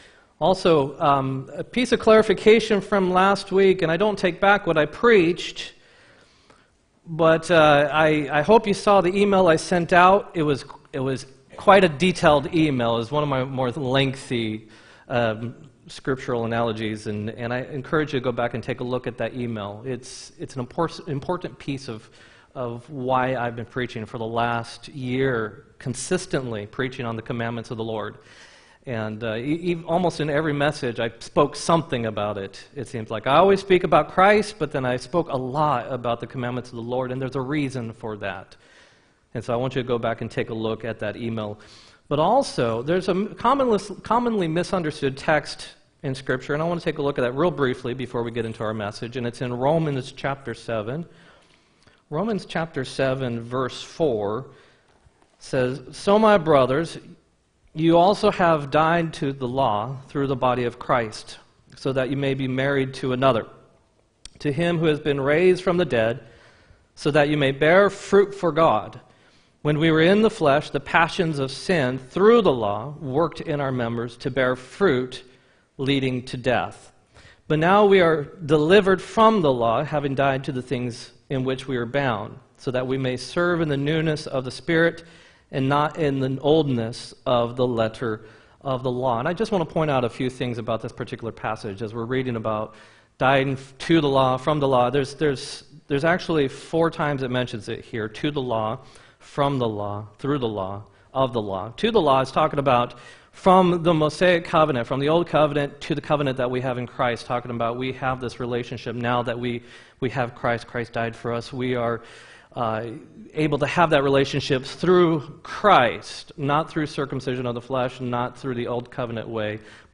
2-2-19 sermon